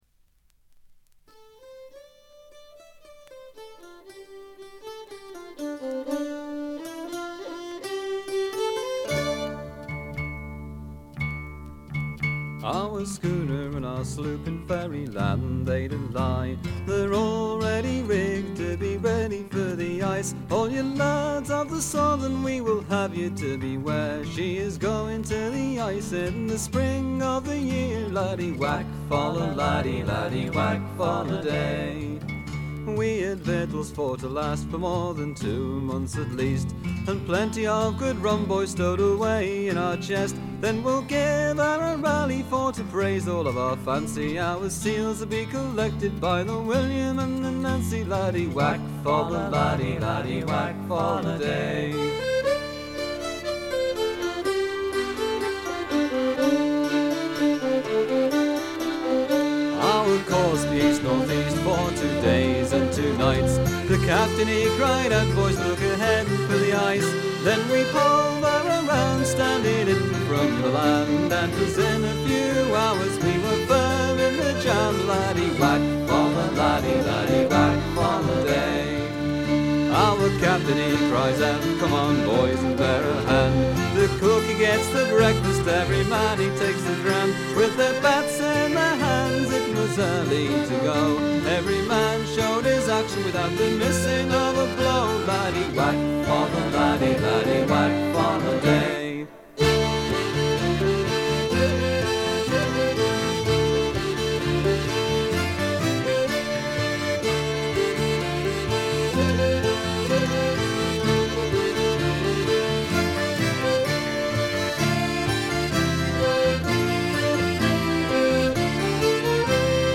70年にエディンバラで結成されたスコットランドを代表するトラッド・バンド。
試聴曲は現品からの取り込み音源です。
Fiddle, viola, bouzouki, mandolin, mandola, vocals
Guitar, mandola